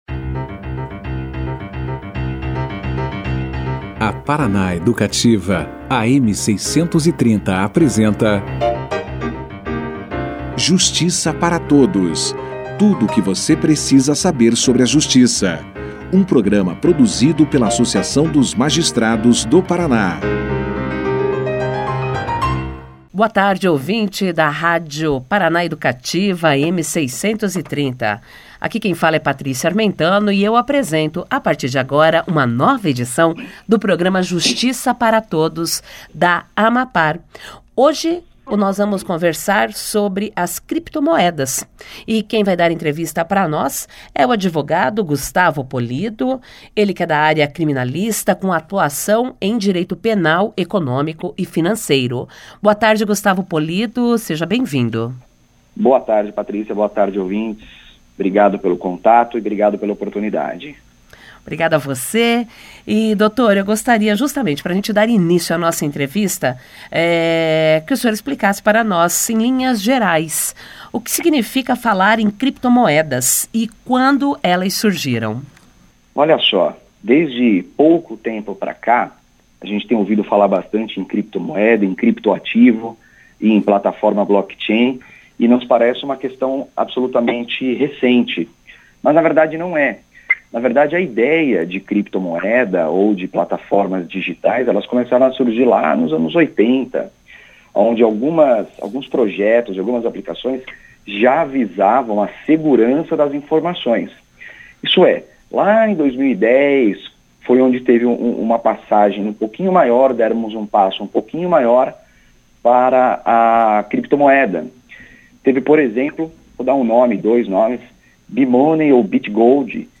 Criptomoedas foi o tema debatido no programa de rádio da AMAPAR, Justiça para Todos na segunda-feira (17).